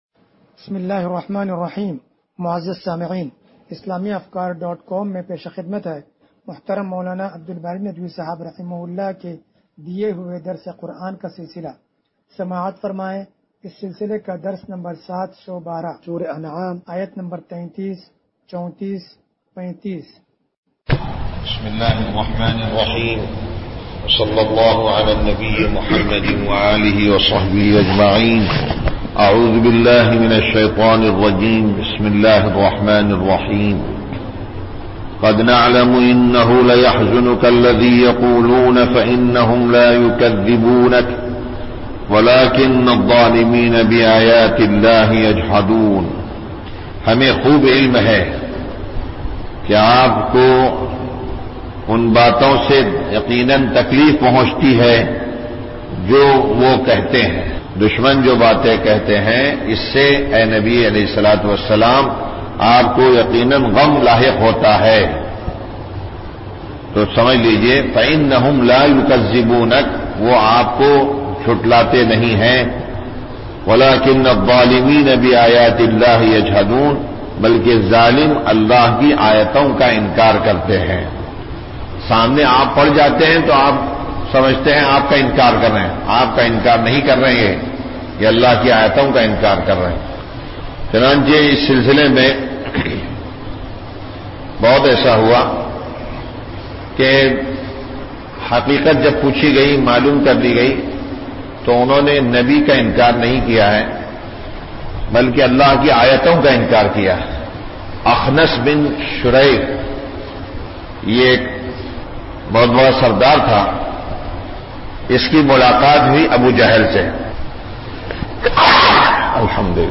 درس قرآن نمبر 0712